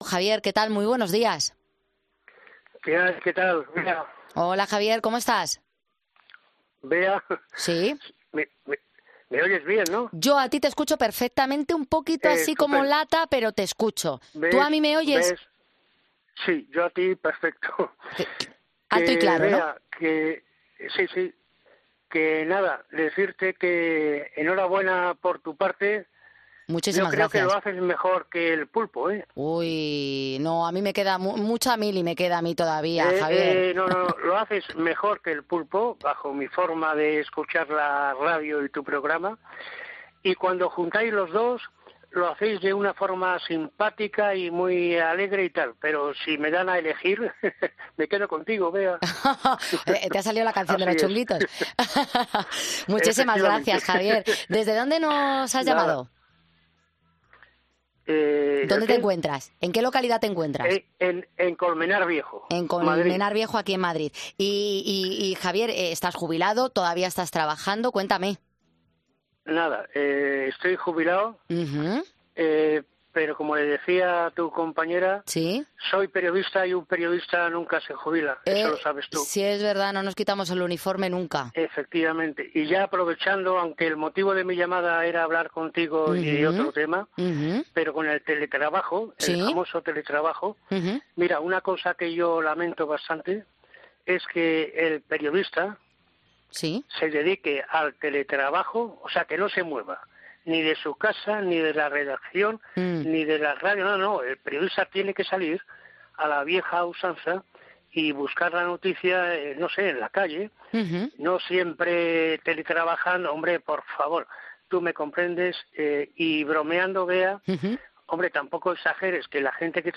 Escucha a este oyente de Poniendo las Calles que era periodista y plantea varios debates